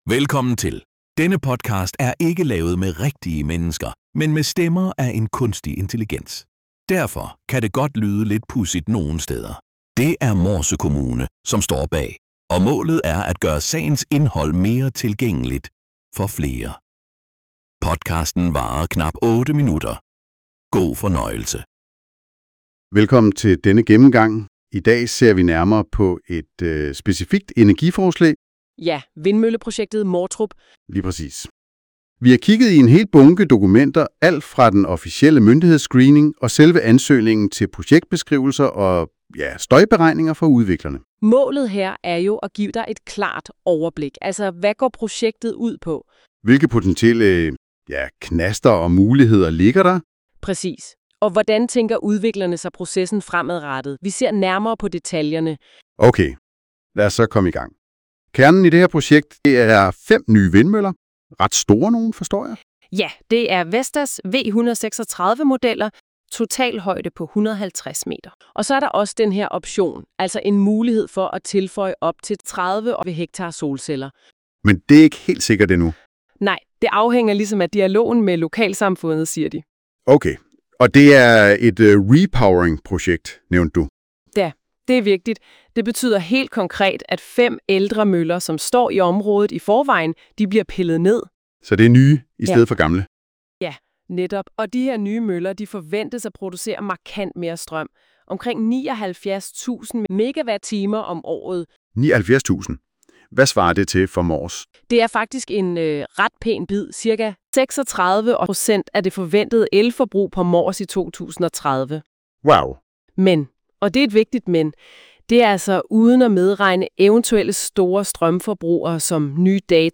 Podcasten er ikke lavet med rigtige mennesker, men med stemmer af en kunstig intelligens. Derfor kan det godt lyde lidt pudsigt nogen steder.